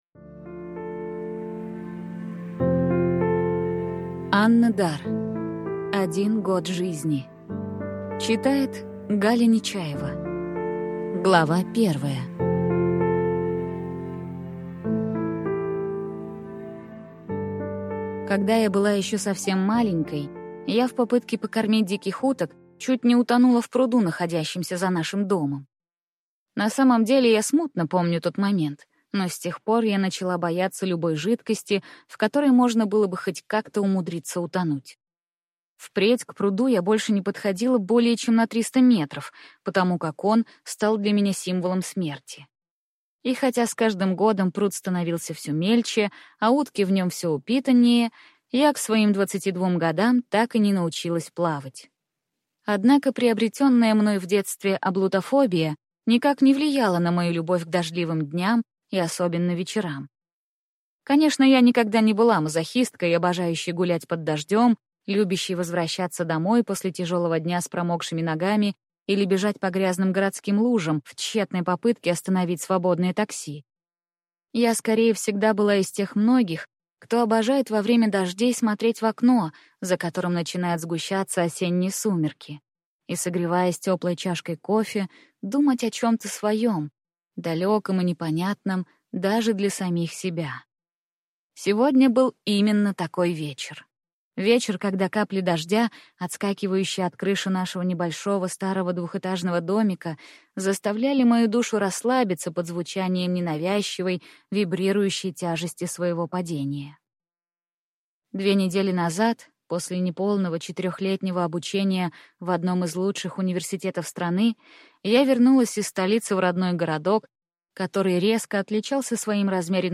Аудиокнига Один год жизни | Библиотека аудиокниг